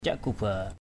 /caʔ-ku-ba:/ (d.) nội các; triều thần.